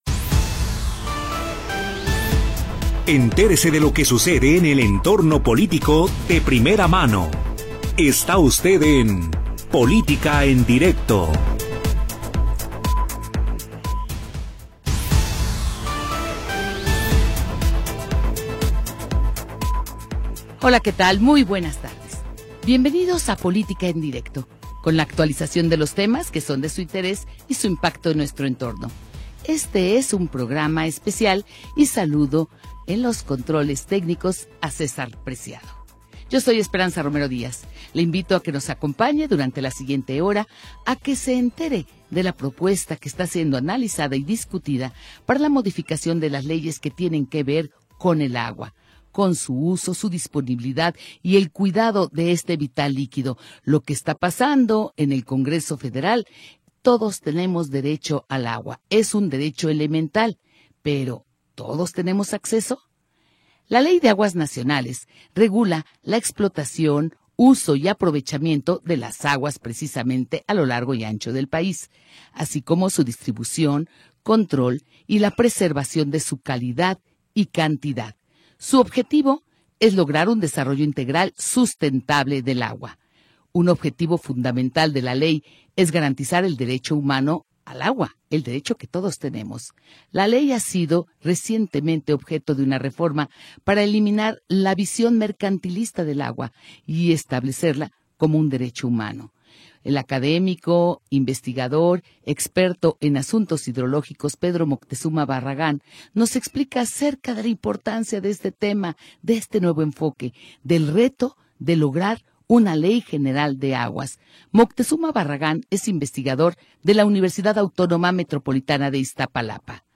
Programa transmitido el 14 de Noviembre de 2025.